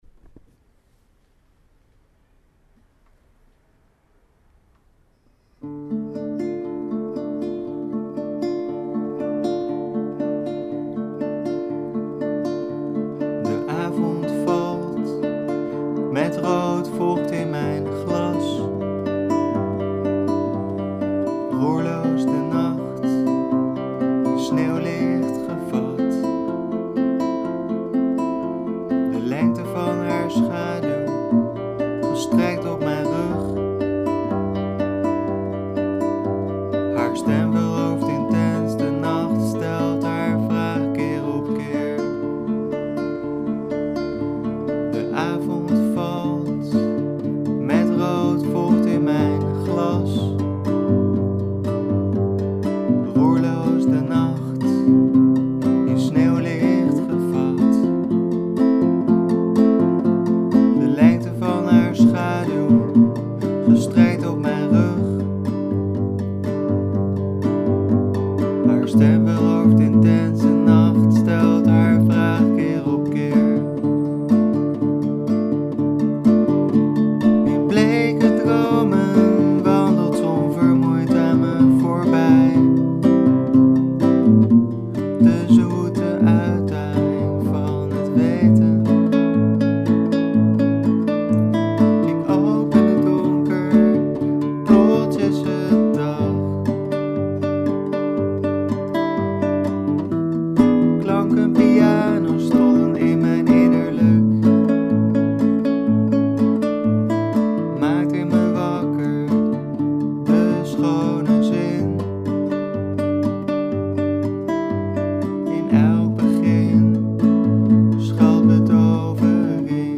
Studio-opnames van ‘Zoete uitdaging’
Twee versies in de studio opgenomen